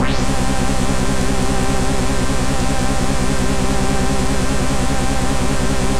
Index of /90_sSampleCDs/Trance_Explosion_Vol1/Instrument Multi-samples/Scary Synth
C2_scary_synth.wav